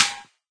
plasticmetal2.ogg